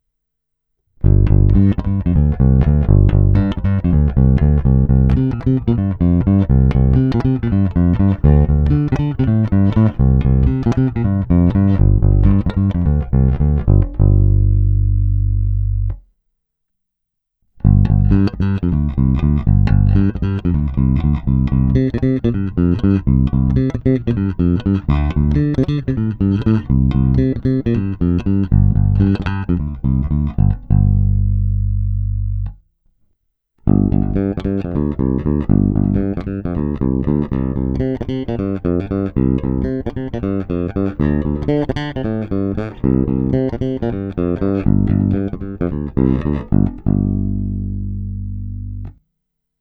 Skvělý zvuk na prsty, stejně tak na slap.
Není-li uvedeno jinak, následující nahrávky jsou provedeny rovnou do zvukové karty a s plně otevřenou tónovou clonou.